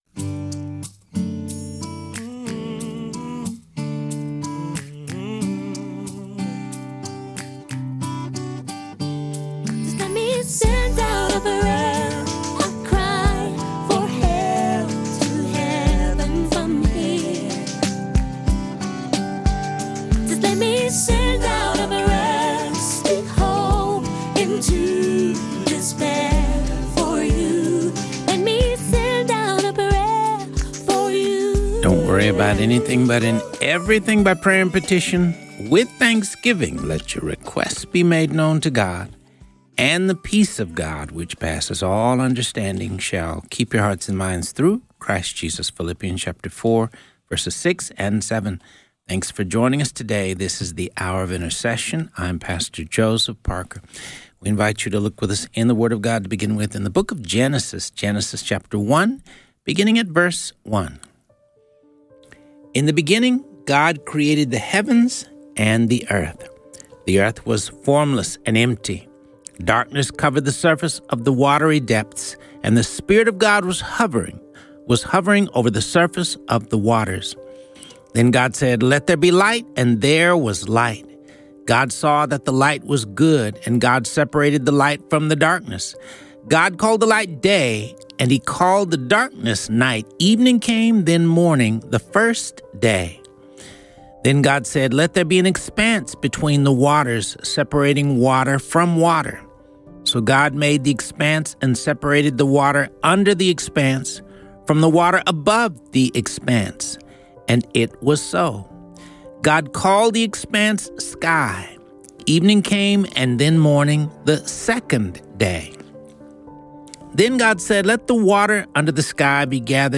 This show covers topics of prayer, intercession, the Word of God and features interviews with pastors and religious leaders.